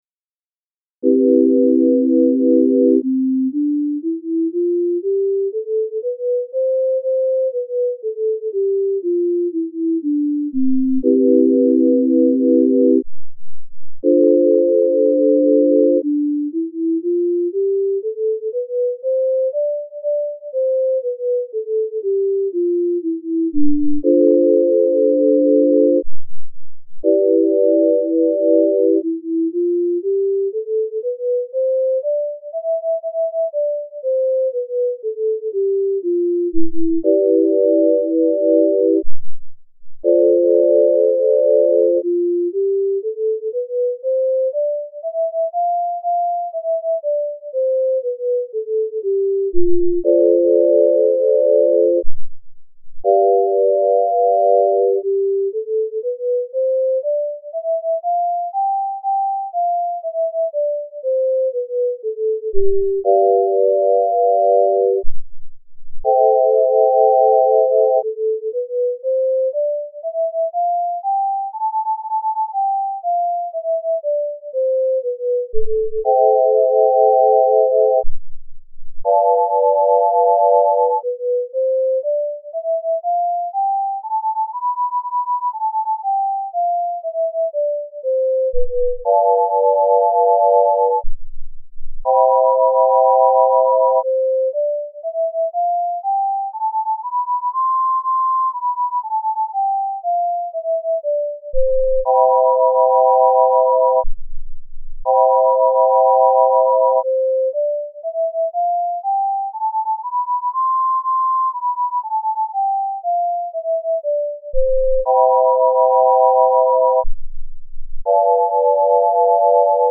C-Major Scale Using the Pythagorean Scale on the Left Ear and the Just Scale on the Right Ear